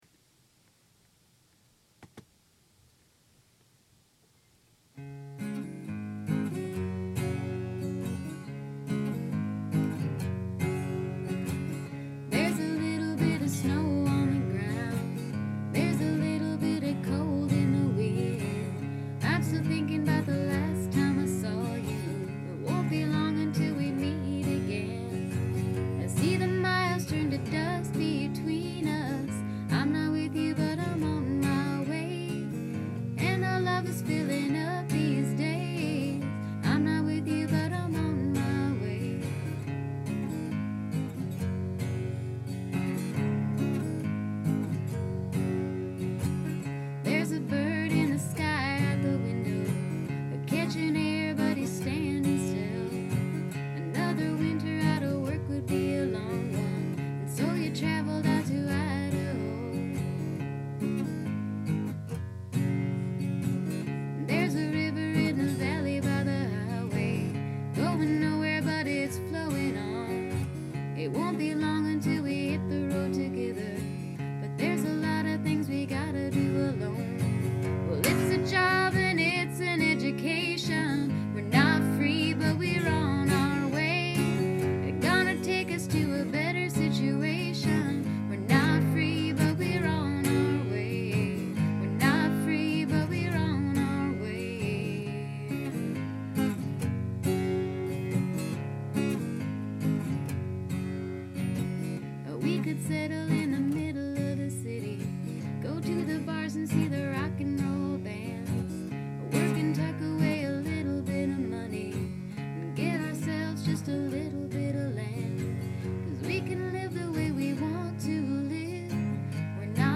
Yay! More low-fi recordings!
As it is, its sort of an earnest, simple, poppy song.
• I like the way a lot of these lyrics scan, as far as emphases fitting into the rhythm of the tune.